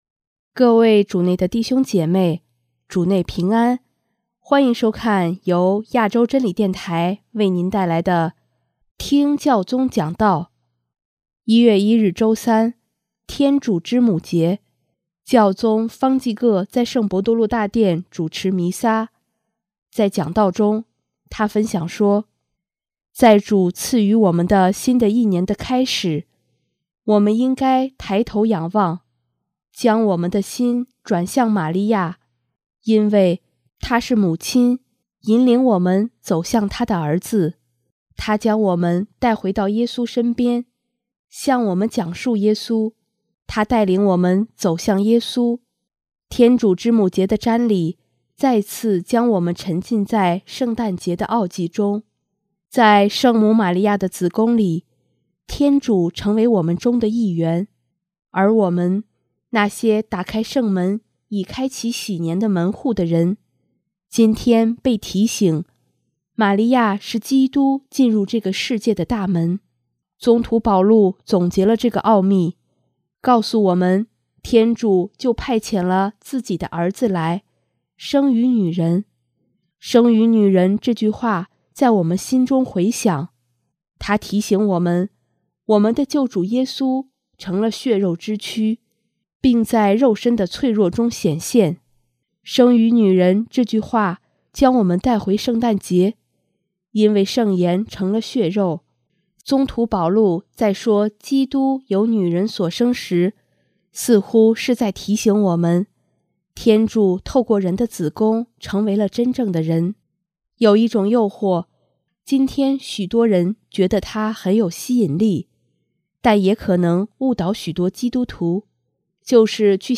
首页 / 新闻/ 听教宗讲道
1月1日周三，天主之母节，教宗方济各在圣伯多禄大殿主持弥撒，在讲道中，他分享说：